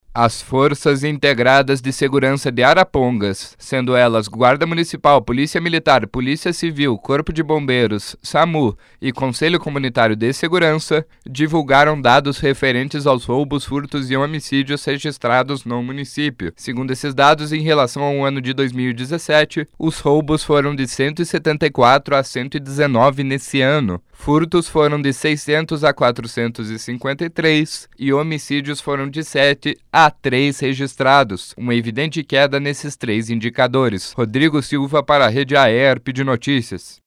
08.06 – BOLETIM SEM TRILHA – Arapongas registra queda em homicídios, furtos e roubos